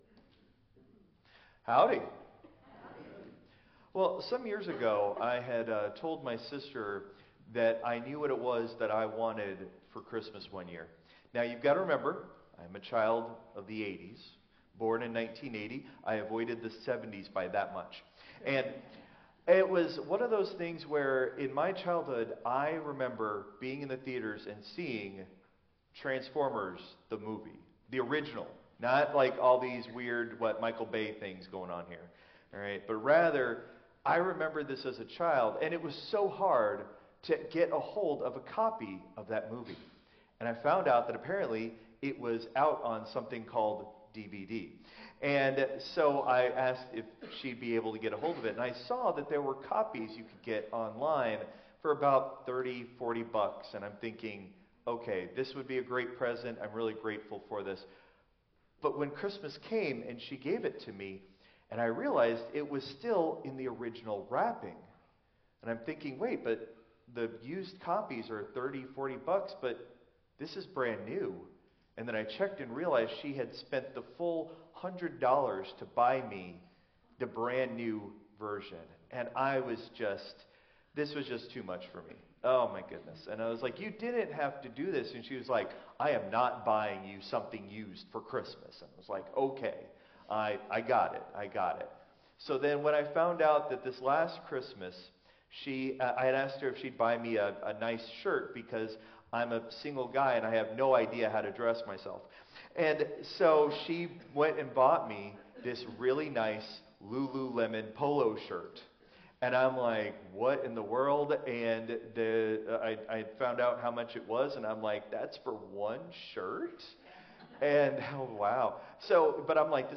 Christ Memorial Lutheran Church - Houston TX - CMLC 2025-02-02 Sermon (Traditional)